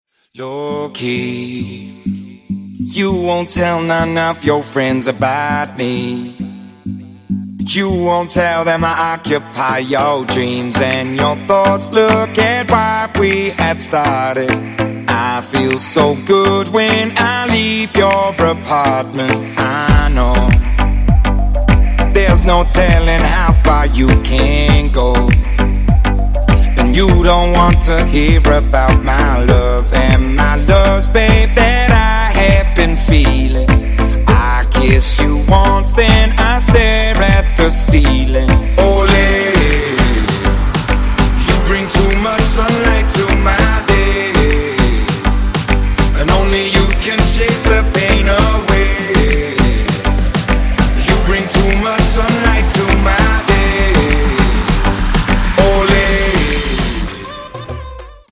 • Качество: 128, Stereo
поп
мужской вокал
dance
vocal